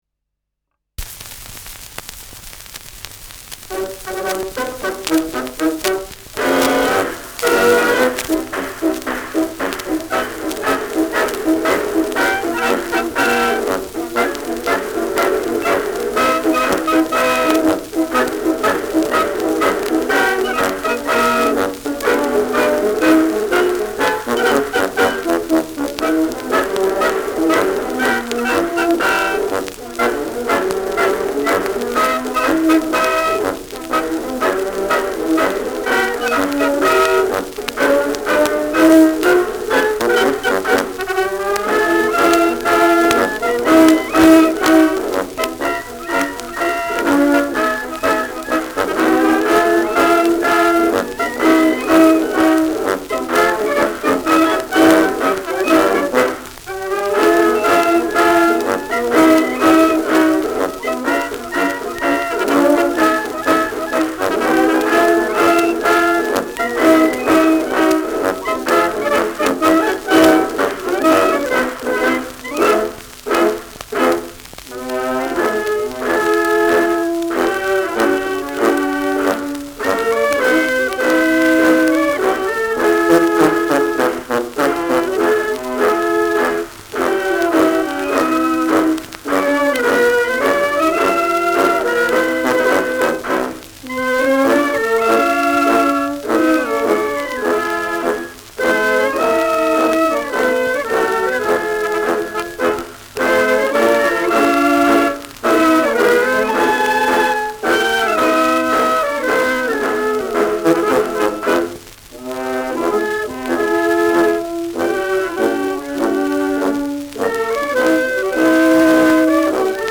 Schellackplatte
Knistern